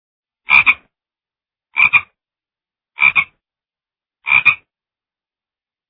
qingwa.mp3